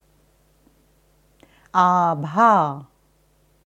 Abha ist ein wichtiges Sanskrit Wort mit gewisser Bedeutung für die Yoga Philosophie. Es wird in der indischen Devanagari Schrift geschrieben आभा, in der wissenschaftlichen IAST Transliteration ābhā. Hier kannst du hören, wie man ganz korrekt das Wort Abha ausspricht.